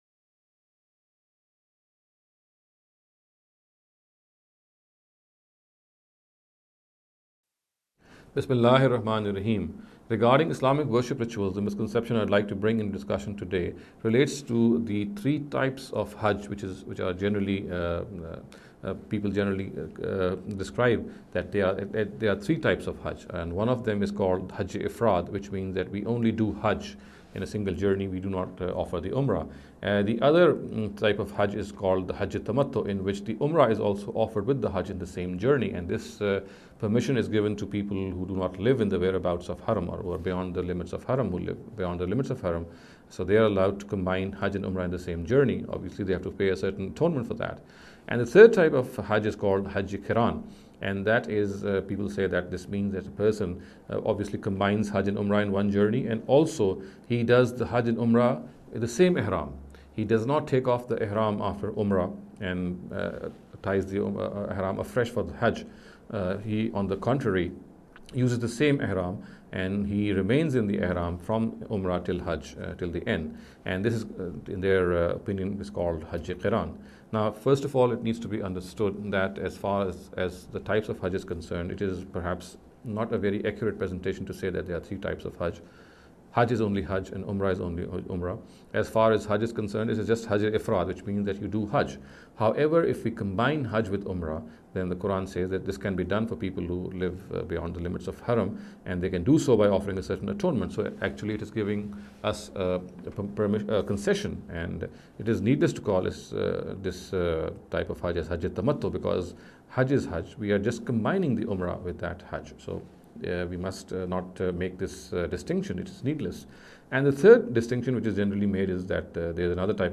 This lecture series will deal with some misconception regarding the Islamic Worship Rituals.